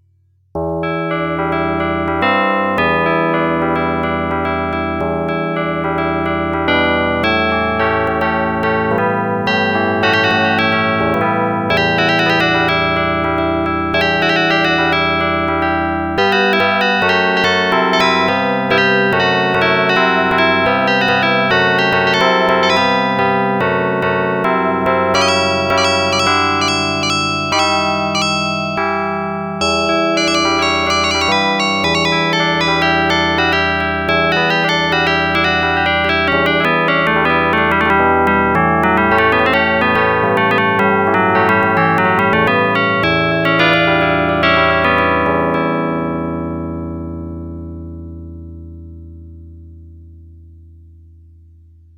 stereo blande.
Le resultato ha un pannation de 70% de cata canal.
MildStereo.ogg